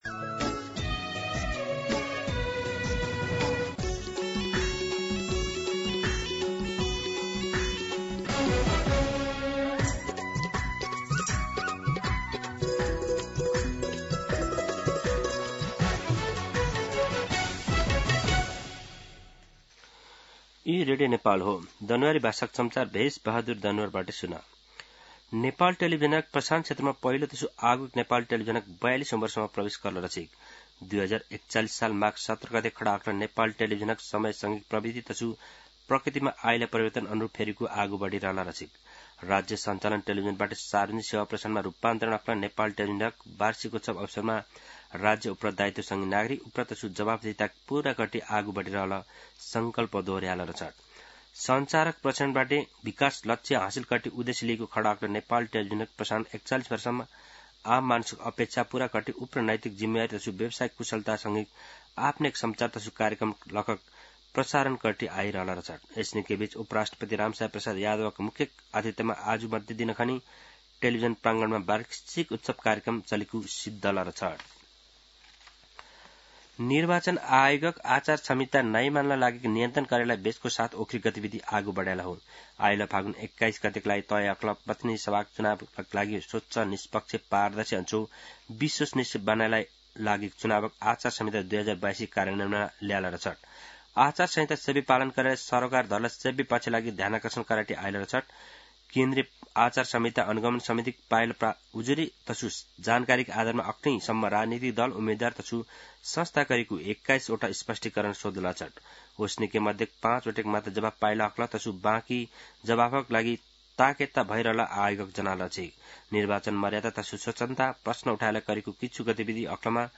दनुवार भाषामा समाचार : १७ माघ , २०८२
Danuwar-News-10-17.mp3